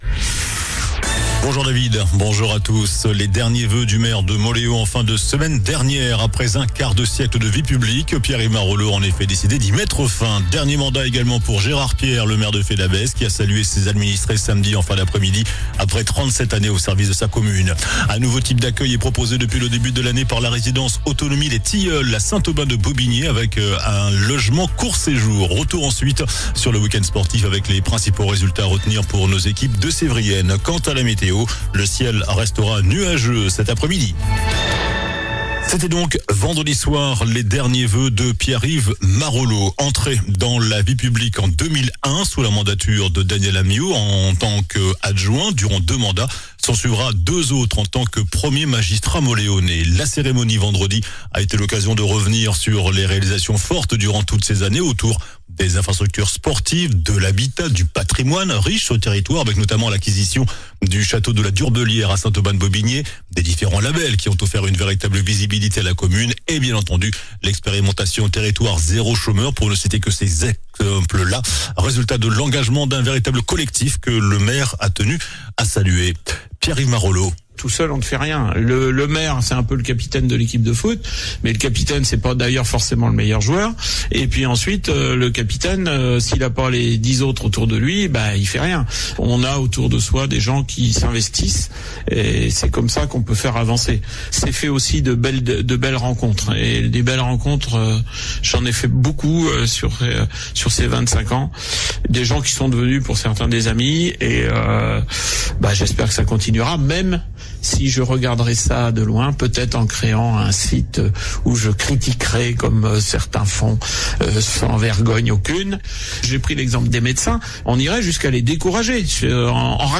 JOURNAL DU LUNDI 19 JANVIER ( MIDI )